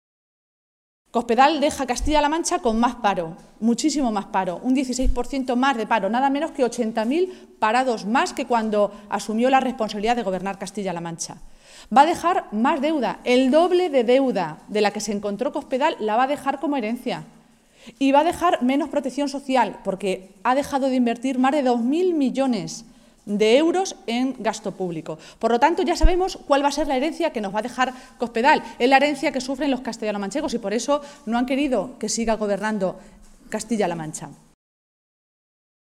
Maestre se pronunciaba de esta manera esta mañana, en Toledo, en una comparecencia ante los medios de comunicación en la que, además de asegurar que se va a estudiar la legalidad de esas decisiones, las ha definido como “poco éticas”.
Cortes de audio de la rueda de prensa